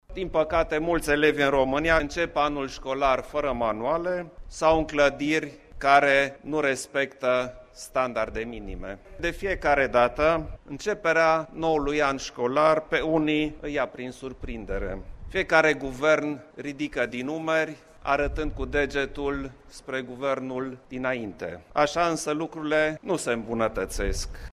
România dedicată – în care elevul este în centrul sistemului de învăţământ, este o prioritate – afirma, astăzi, preşedintele Klaus Ioahnnis la festivitateade deschidere a unui colegiu din capitală.